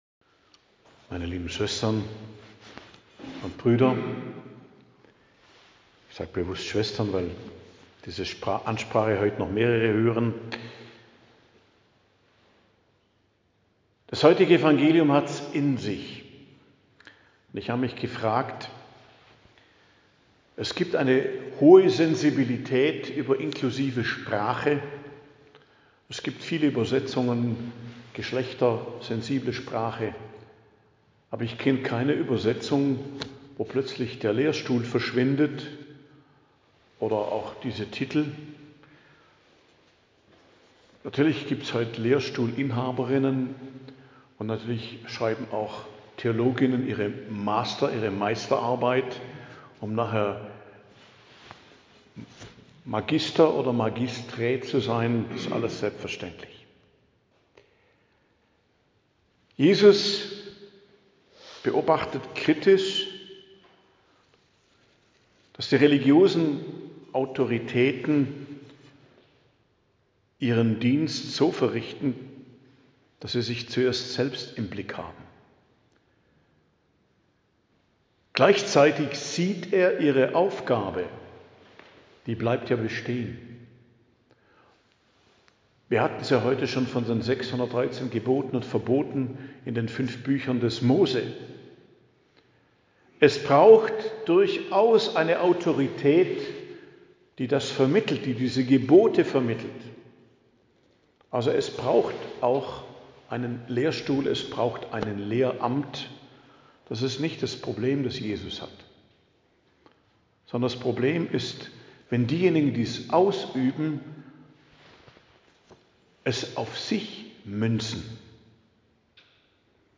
Predigt am Dienstag der 2. Woche der Fastenzeit, 3.03.2026